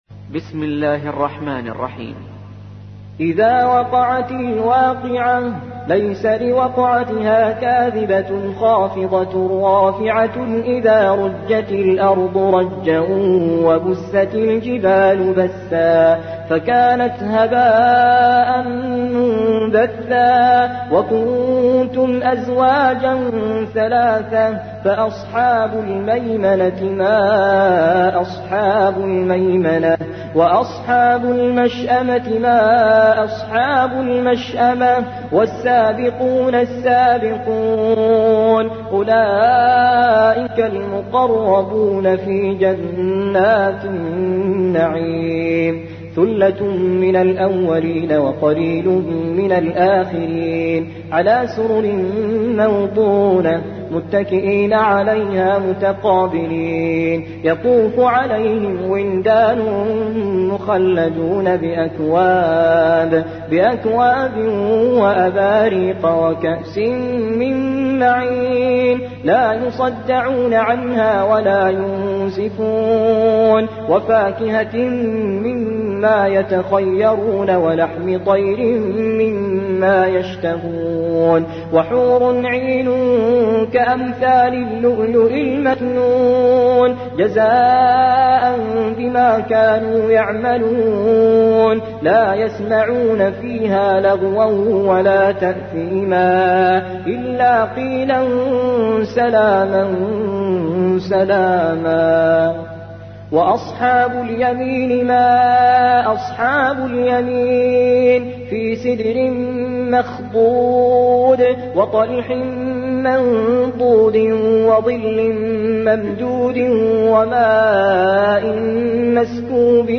56. سورة الواقعة / القارئ